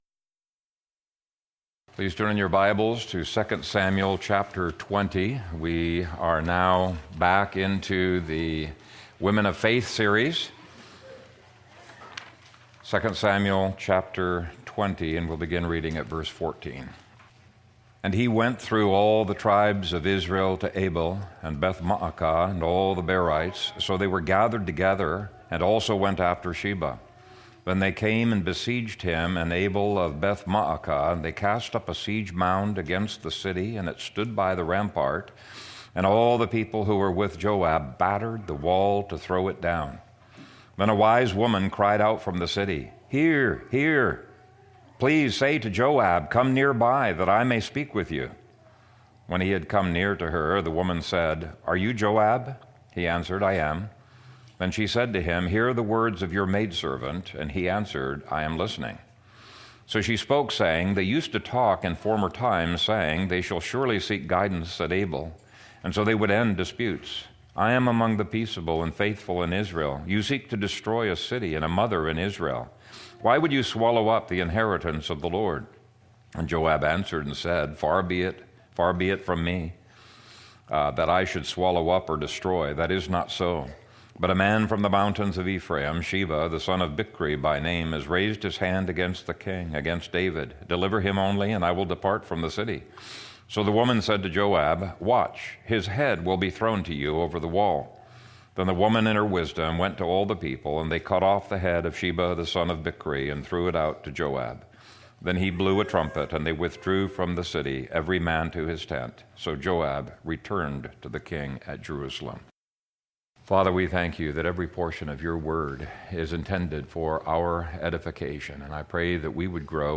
Wise Woman of Abel | SermonAudio Broadcaster is Live View the Live Stream Share this sermon Disabled by adblocker Copy URL Copied!